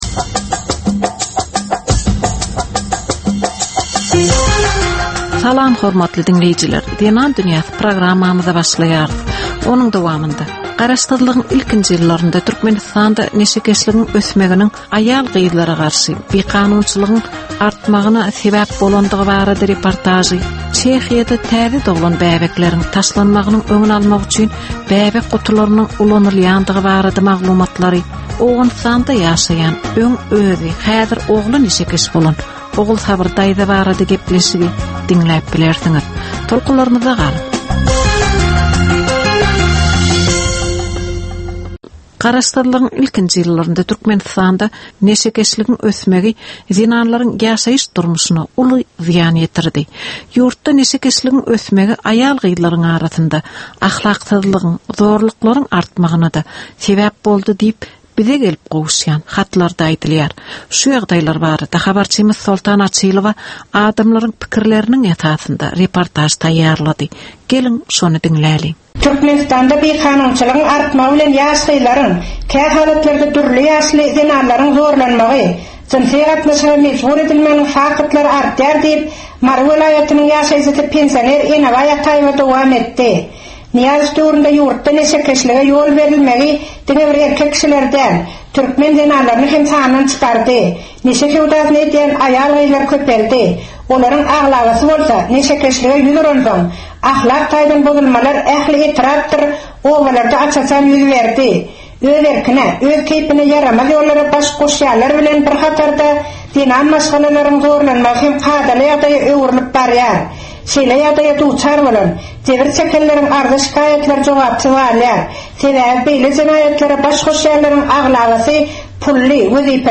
Türkmen we halkara aýal-gyzlarynyň durmuşyna degişli derwaýys meselelere we täzeliklere bagyşlanylyp taýýarlanylýan ýörite gepleşik. Bu gepleşikde aýal-gyzlaryň durmuşyna degişli maglumatlar, synlar, bu meseleler boýunça synçylaryň we bilermenleriň pikrileri, teklipleri we diskussiýalary berilýär.